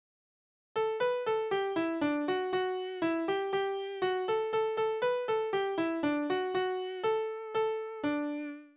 « O-2620 » Mariechen saß auf einem Stein Kindertänze Mariechen saß auf einem Stein, einem Stein, einem Stein, Mariechen satz auf einem Stein, einem Stein. Tonart: D-Dur Taktart: 2/4 Tonumfang: große Sexte Besetzung: vokal Externe Links